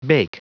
Prononciation du mot bake en anglais (fichier audio)
Prononciation du mot : bake